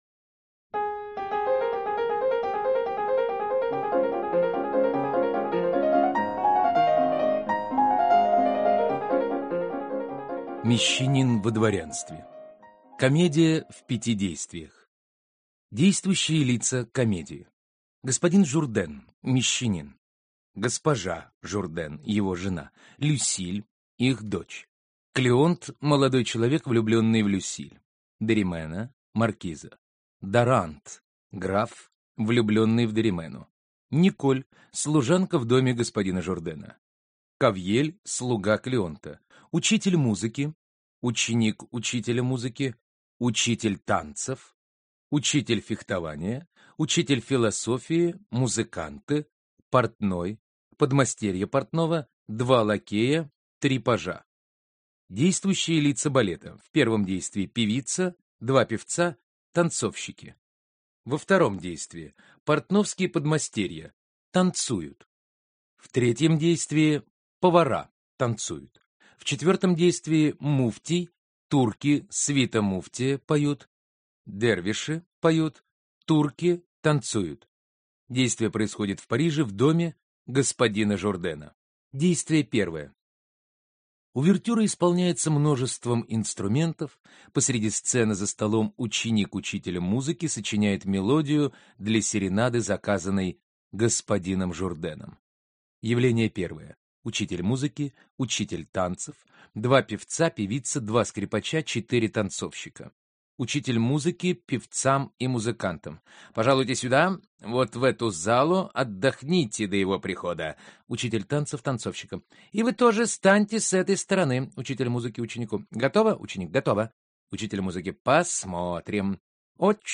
Аудиокнига Мещанин во дворянстве.